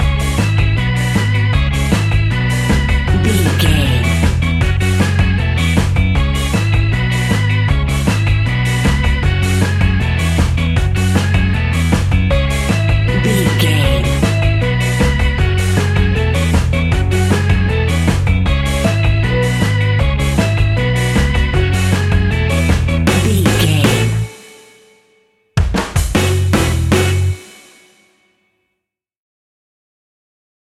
Classic reggae music with that skank bounce reggae feeling.
Aeolian/Minor
F#
reggae instrumentals
laid back
chilled
off beat
drums
skank guitar
hammond organ
percussion
horns